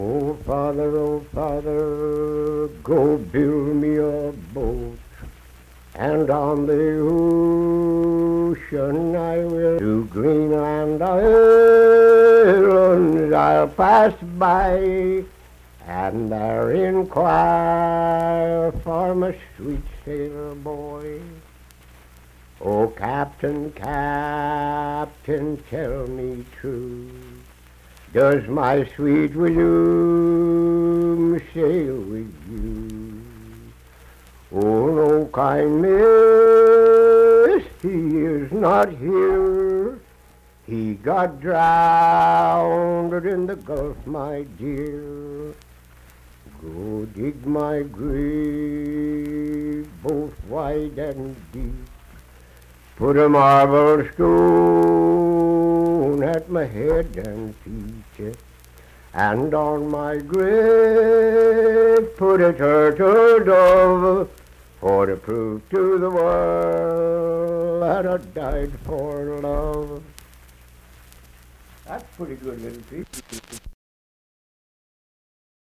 Unaccompanied vocal music performance
Verse-refrain 3(4).
Voice (sung)